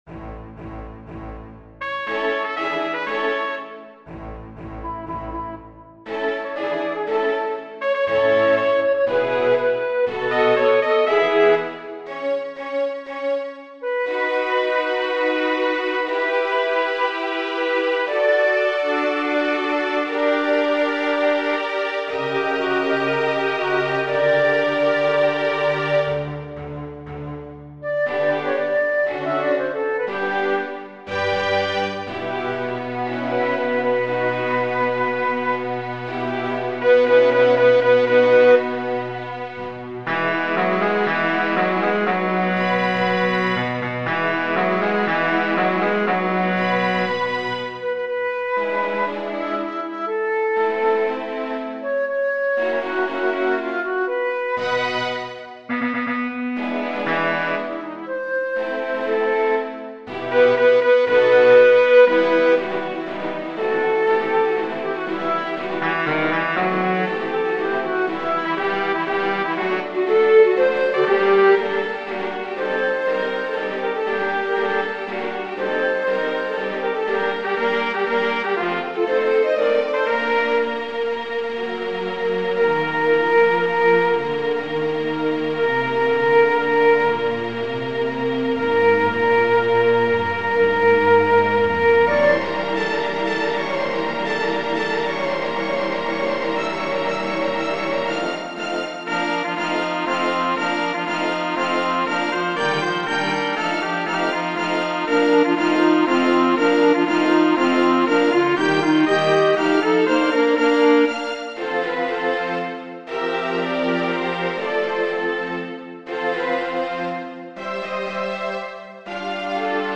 Choeur